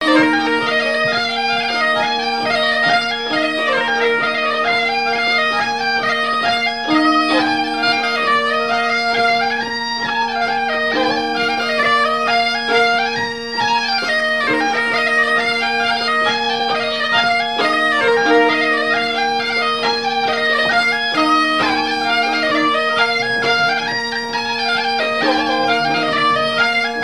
Couplets à danser
branle : courante, maraîchine
Airs joués à la veuze et au violon et deux grands'danses à Payré, en Bois-de-Céné
Pièce musicale inédite